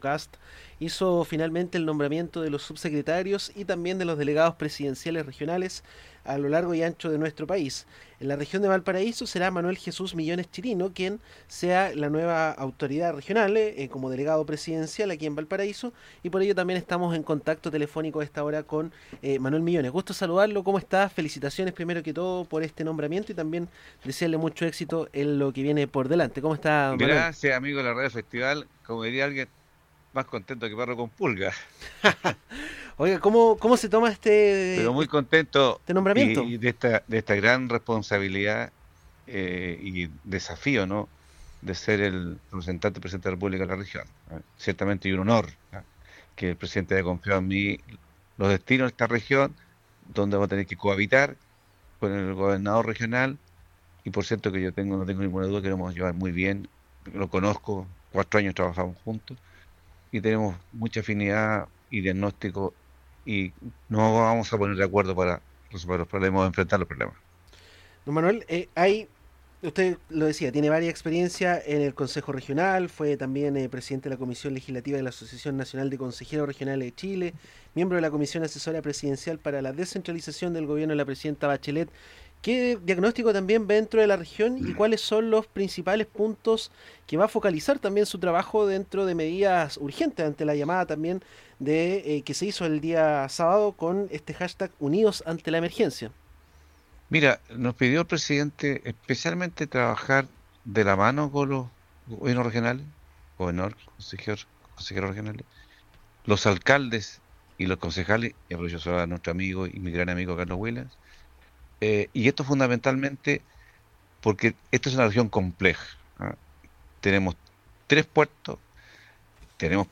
En los estudios de radio Festival estuvo Manuel Millones, quien asumirá el 11 de marzo como nuevo delegado presidencial de Valparaíso. Conversamos sobre seguridad, trabajo, vivienda entre otros temas.